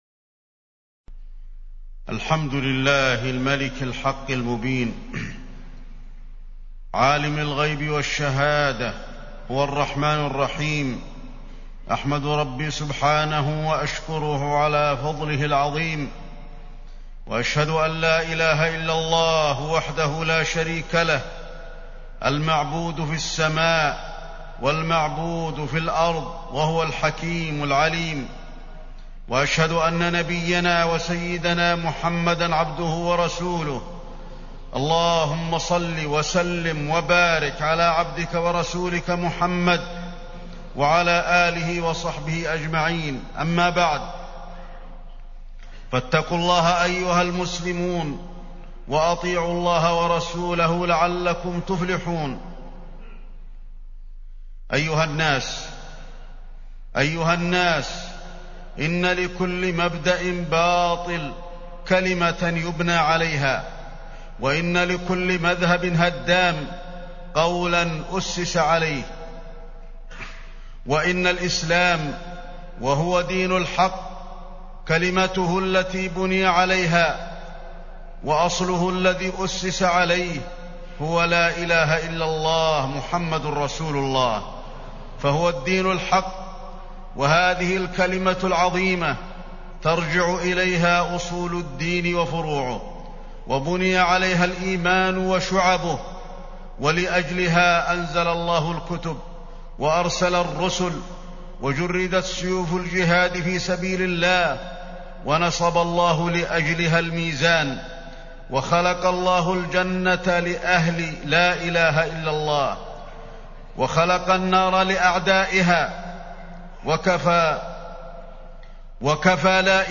تاريخ النشر ١٥ شعبان ١٤٢٧ هـ المكان: المسجد النبوي الشيخ: فضيلة الشيخ د. علي بن عبدالرحمن الحذيفي فضيلة الشيخ د. علي بن عبدالرحمن الحذيفي شهادة أن لا إله إلا الله